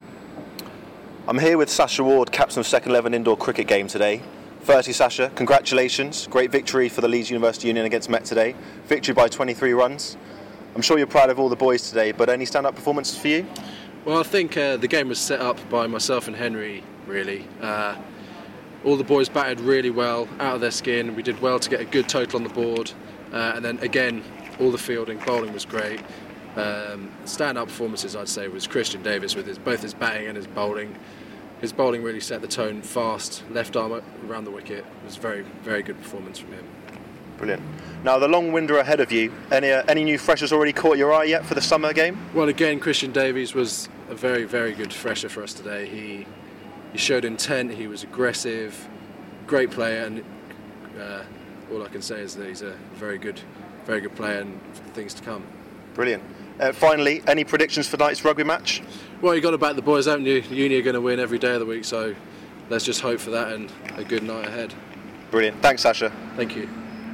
Quick interview